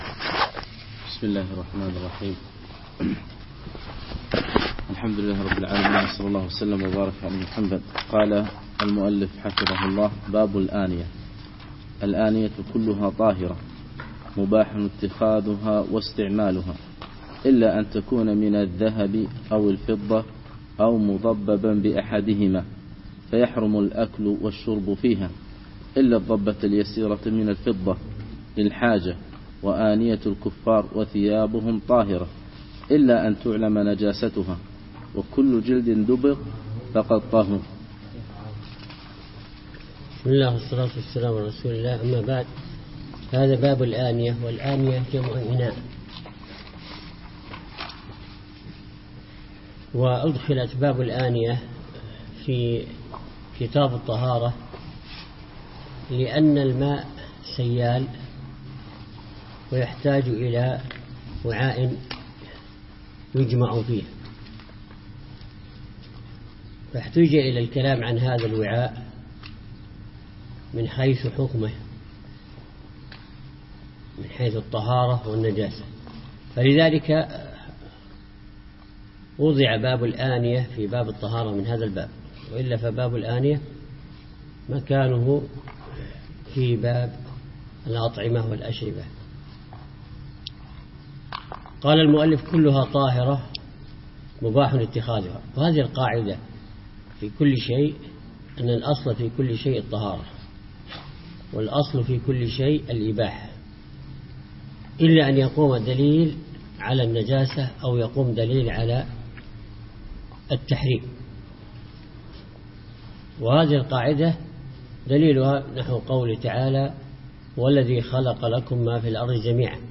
يوم الجمعة 6 3 2015 بعد صلاة الفجر في مسجد براك العواجي منطقة اشبيليا